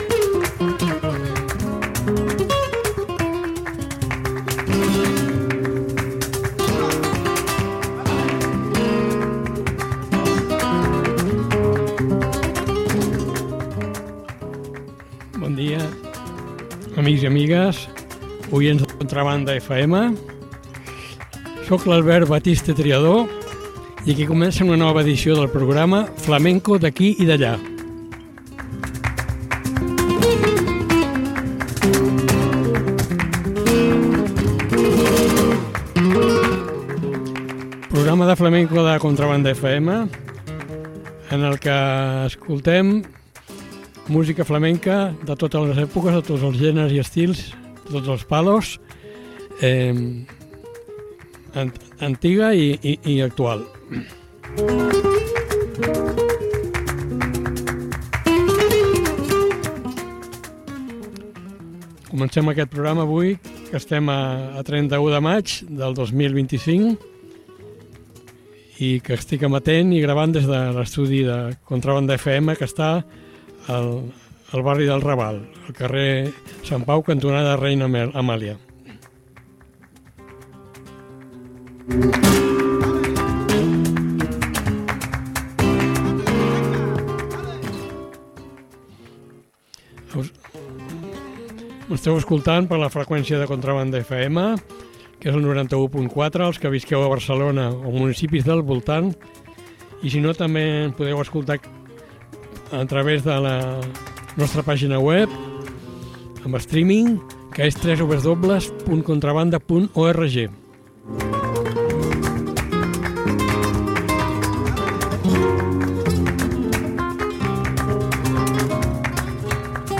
Cantes flamencos resultat dels intercanvis culturals amb els països de l’antic imperi espanyol.